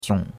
jiong3.mp3